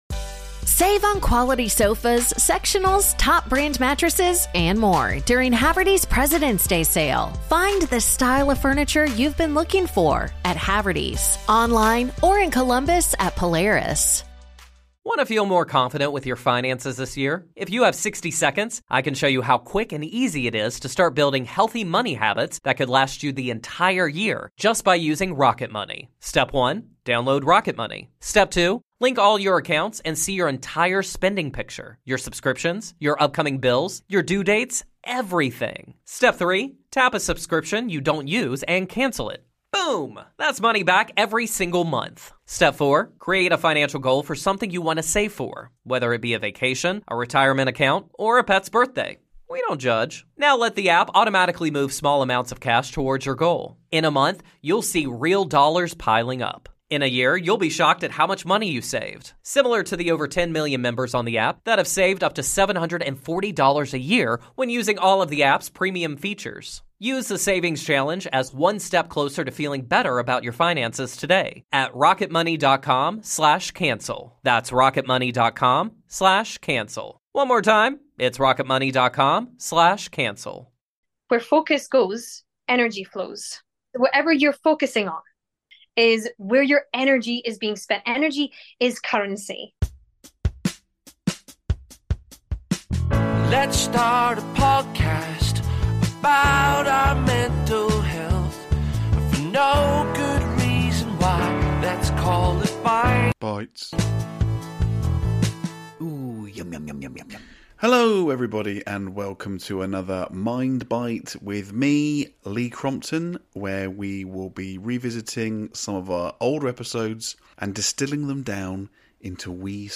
This is visualisation without the woo-woo — a playful, grounded chat about focus, energy, psychology and noticing the things that matter (not just tiny Italian cars).